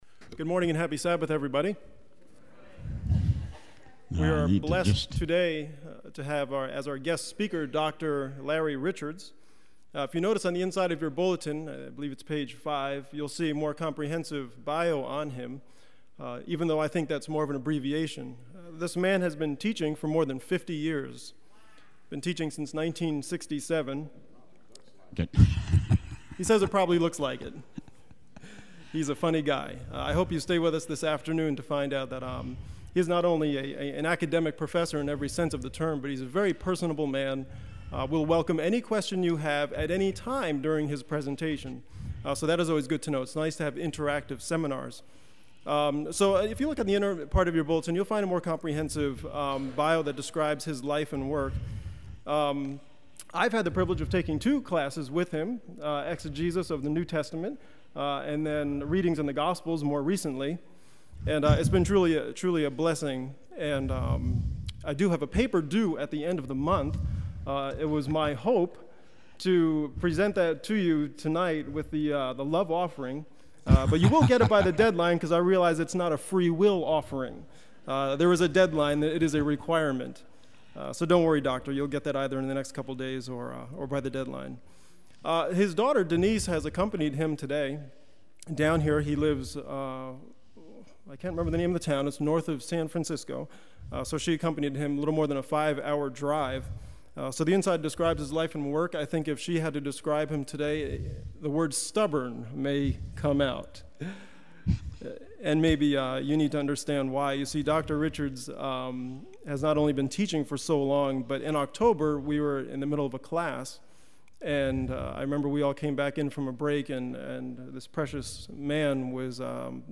Sabbath Sermons